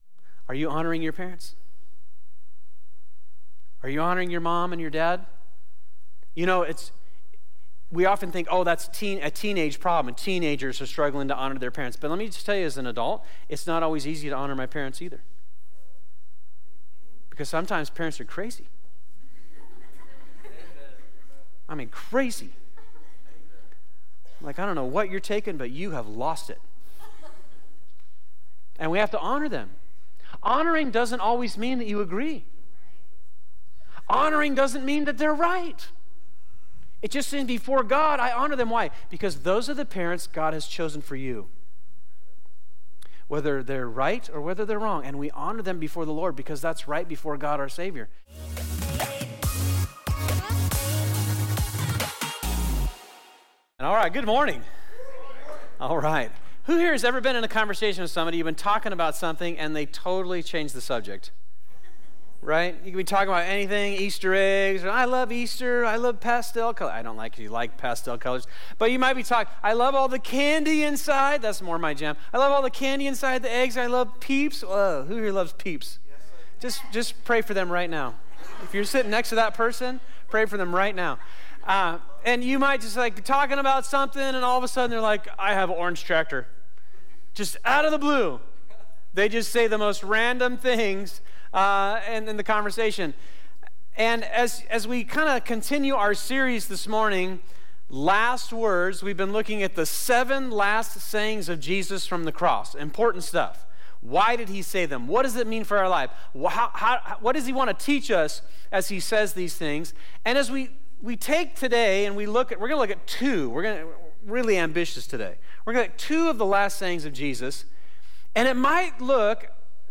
This is part 3 of "Last Words," our sermon series at Fusion Christian Church where we examine the final seven sayings of Jesus before he died.